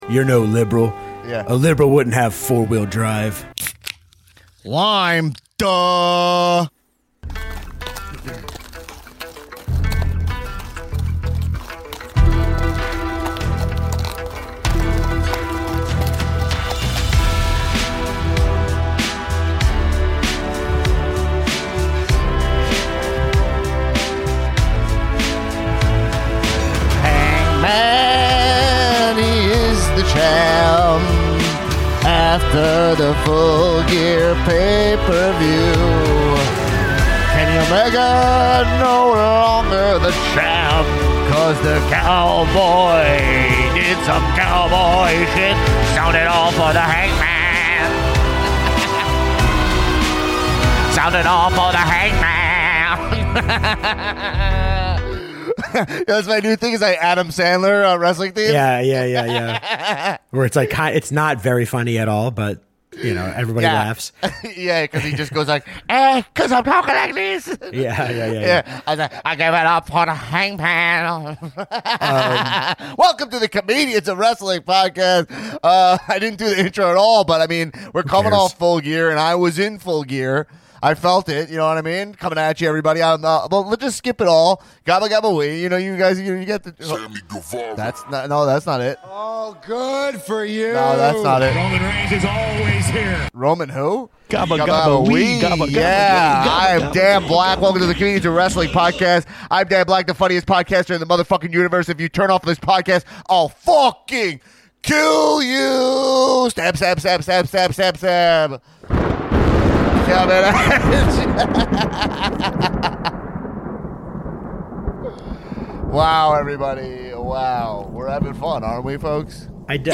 improvises the lyrics to the Hangman theme song